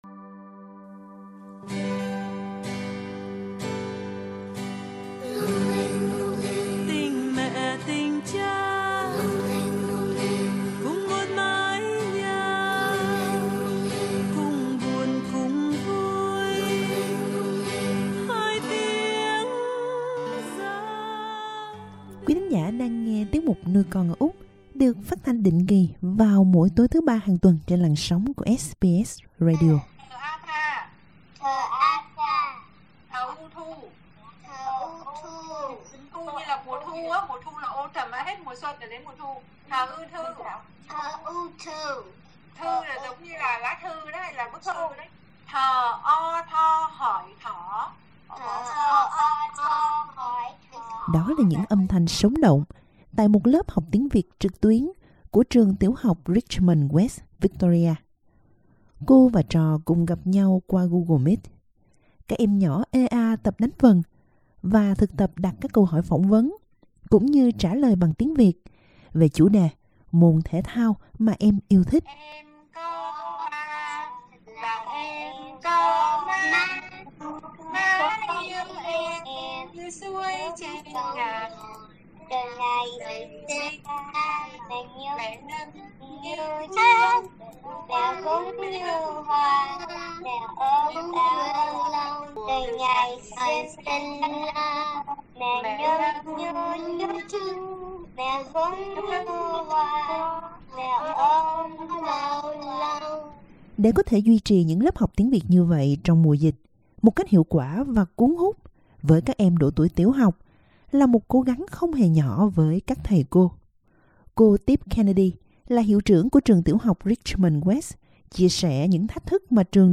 Các lớp tiếng Việt vẫn "sáng đèn" Những âm thanh sống động tại một lớp học tiếng Việt trực tuyến của trường tiểu học Richmond West.
Các em nhỏ ê a tập đánh vần, và thực tập đặt các câu hỏi phỏng vấn và trả lời bằng tiếng Việt về chủ đề môn thể thao mà em yêu thích.